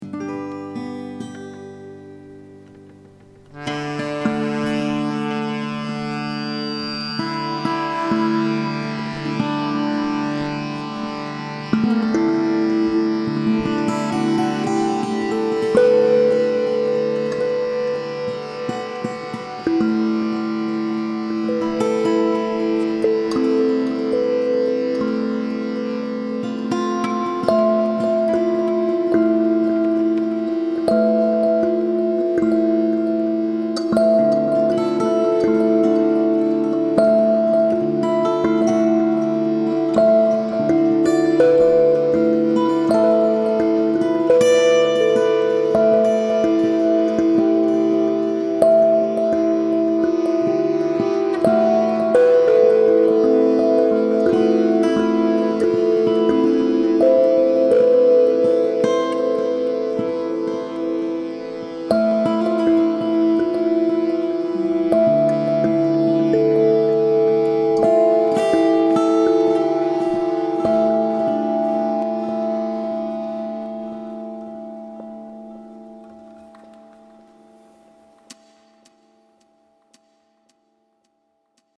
Sansula.mp3